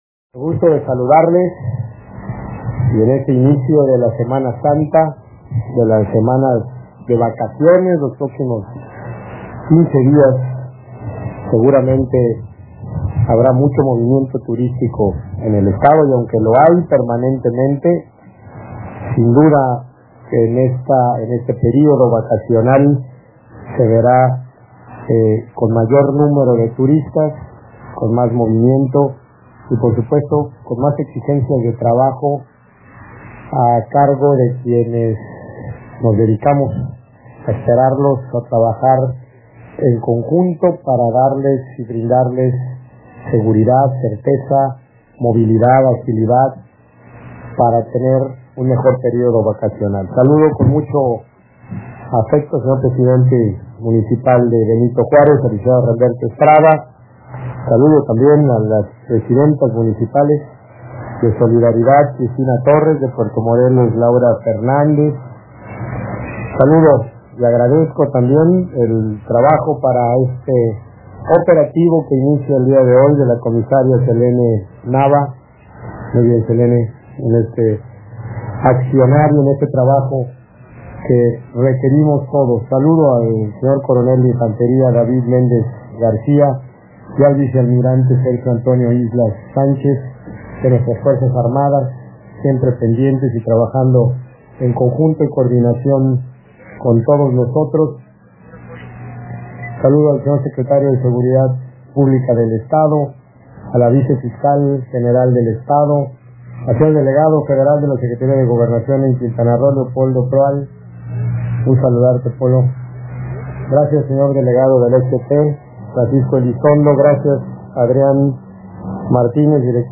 Escucha el Mensaje del Gobernador :
Mensaje-del-Gobernador-Carlos-Joaquín-en-el-Banderazo-de-inicio-del-Operativo-Semana-Santa-2017.mp3